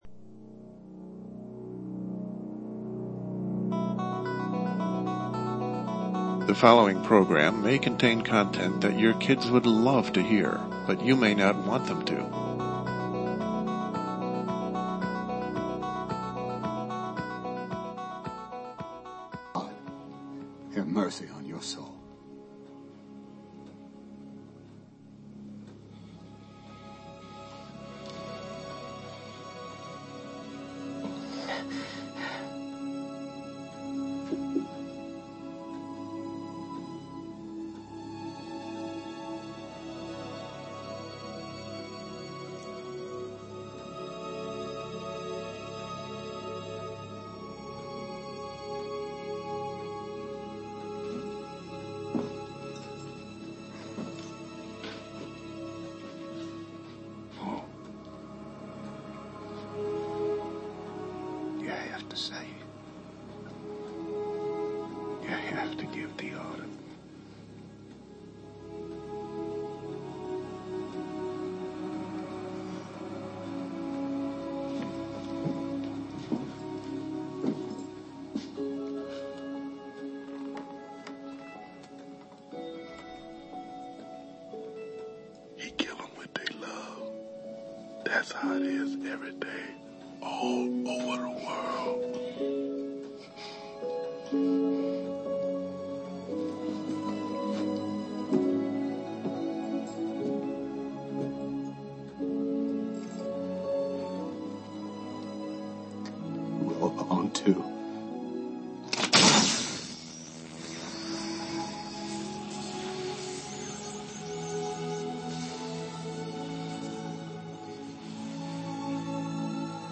LIVE, Thursday, Oct. 11 at 9 p.m. LIVE. Conspiracy theories are lousy on the Internet and hoards of people all over the world buy into them, some define their very existence due to them. Check in for our host’s review of those theories which people subscribe that are supported by the ‘net and a brief history of where the conspiracy theory was born.